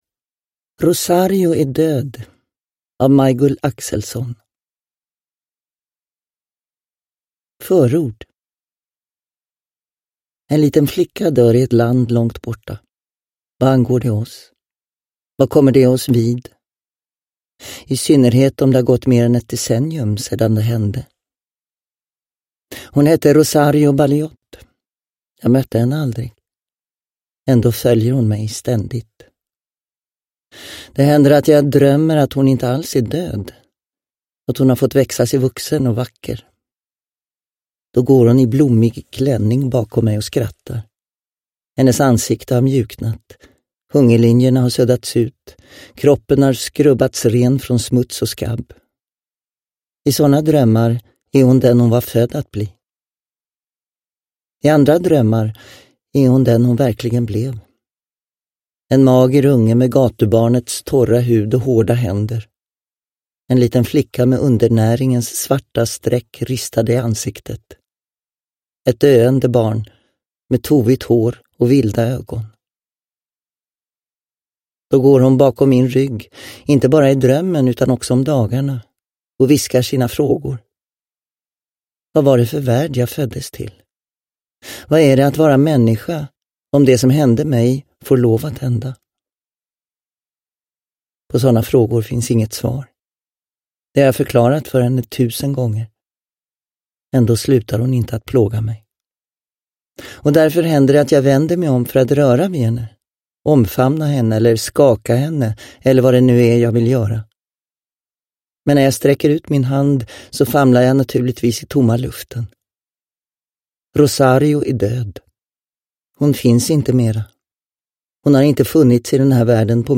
Rosario är död – Ljudbok – Laddas ner
Uppläsare: Gunnel Fred